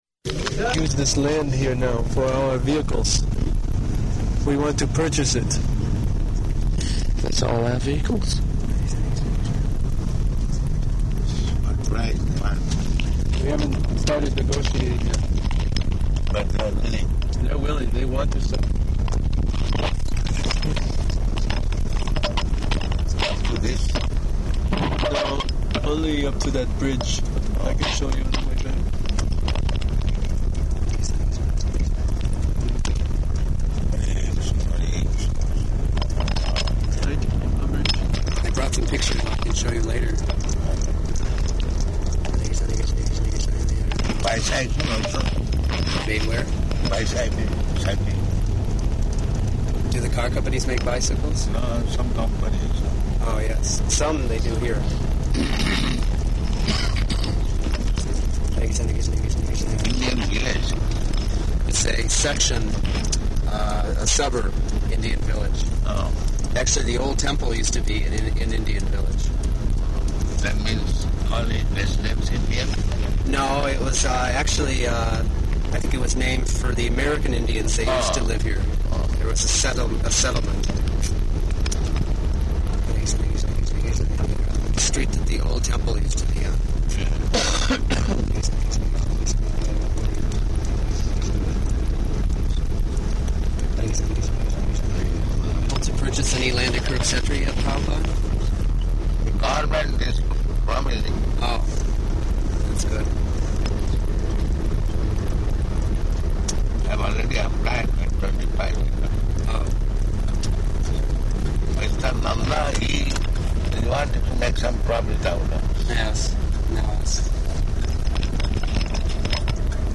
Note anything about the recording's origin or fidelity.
Morning Walk --:-- --:-- Type: Walk Dated: June 13th 1976 Location: Detroit Audio file: 760613MW.DET.mp3 [in car] Devotee (1): ...use this land here now for our vehicles.